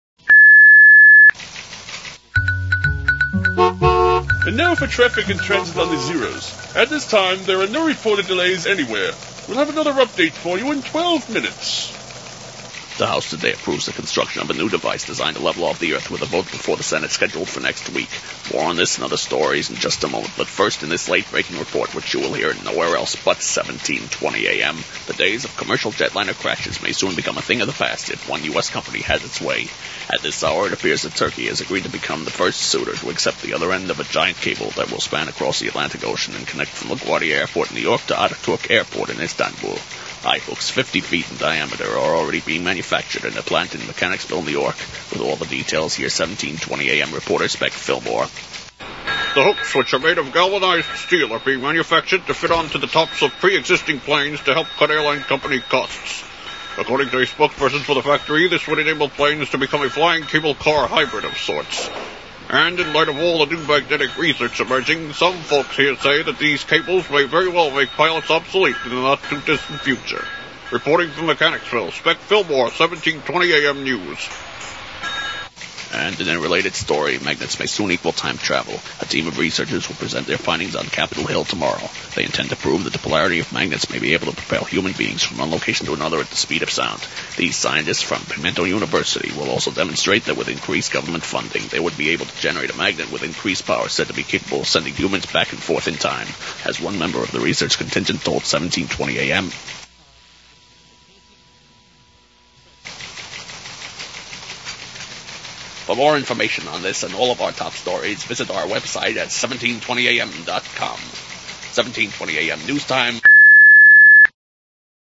I also ended up using the exact same teletype SFX WINS used.
Here are the "airchecks" of the fictitious "1720" station (about which one person on a board relating to "Noo Yawk's" broadcasting history said, "Love that Group W font"):
Again, you could pretty much tell which station (and its "sound") was satirized in those.
["In early 2000, 1720 AM made a bold step to shore up its presence in the market by acquiring five used Teletype machines . . . for the purpose of providing that annoying 'clack-clack-clack' sound which is heard over our anchors"]